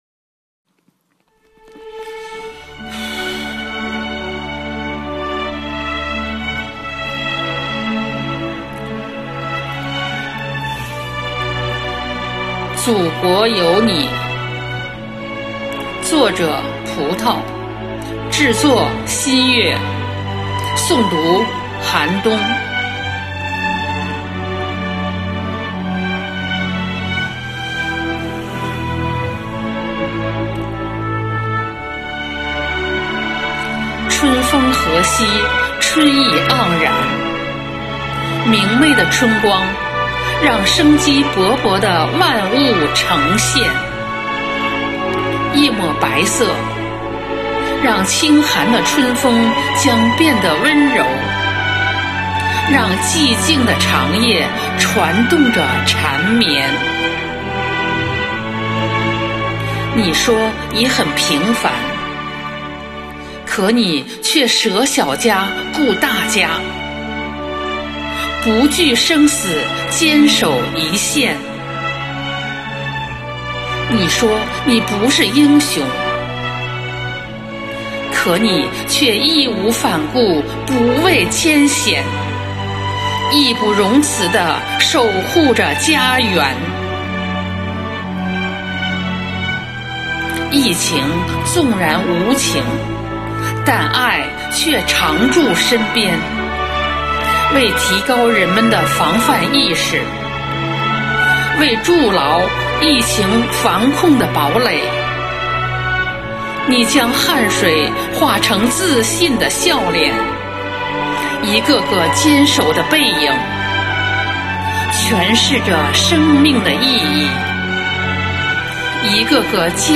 生活好课堂幸福志愿者八里庄西里朗读服务（支）队抓住四月末的尾巴，举办“拥抱春天”主题云朗诵会。